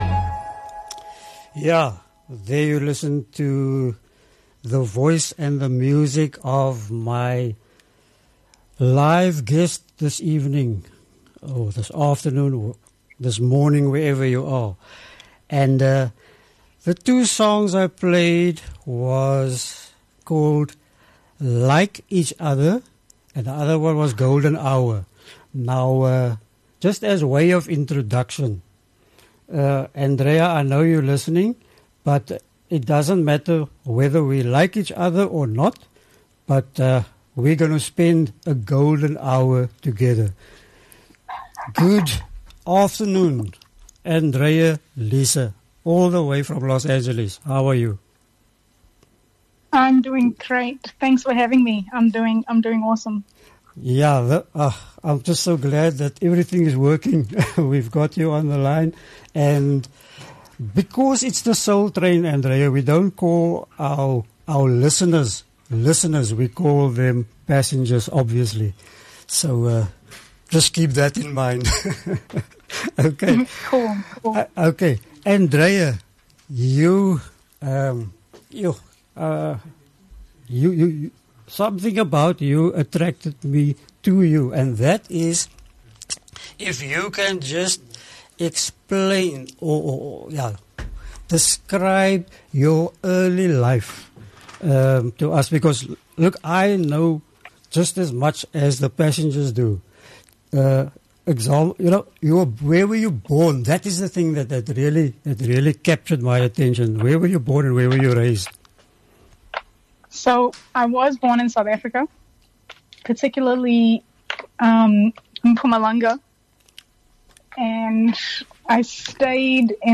Live interview with Musical Artist